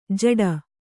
♪ jaḍa